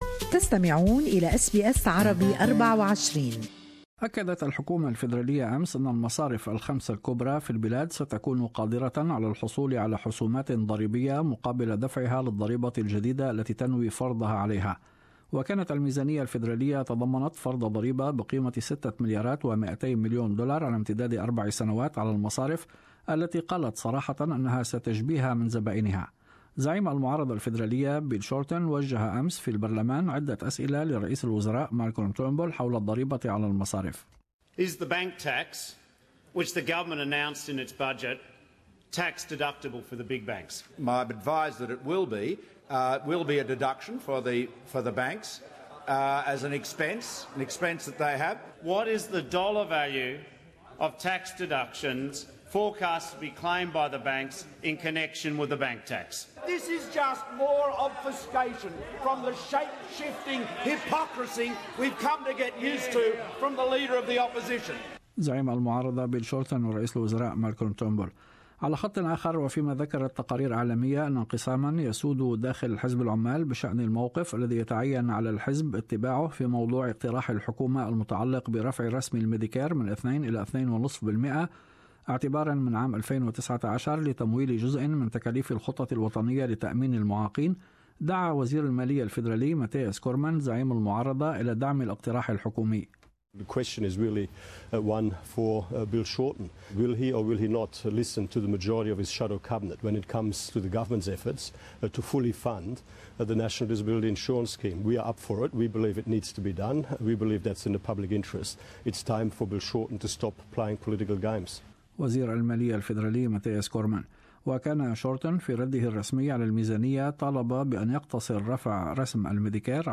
In this bulletin ...